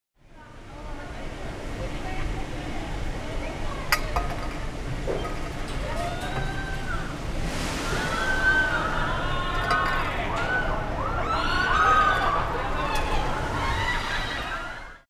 which mixed 2 channels of down with a stereo.
Amusement_Multi_Short_Stereo
03_2A_Amusement_Multi_Short_Stereo.wav